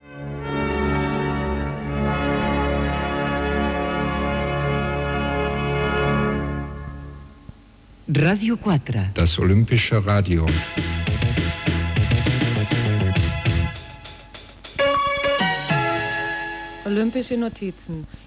2c7c5b563f3bd894f5e34cb8629a7c233f4b7d01.mp3 Títol Ràdio 4 la Ràdio Olímpica Emissora Ràdio 4 la Ràdio Olímpica Cadena RNE Titularitat Pública estatal Descripció Indicatiu de l'emissora en alemany.